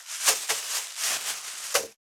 602ゴミ袋,スーパーの袋,袋,
効果音